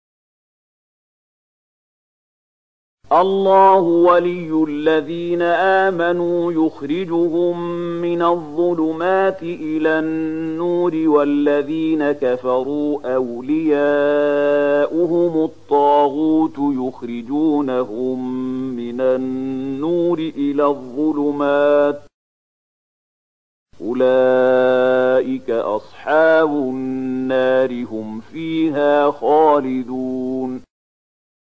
002257 Surat Al-Baqarah ayat 257 dengan bacaan murattal Syaikh Mahmud Khalilil Hushariy: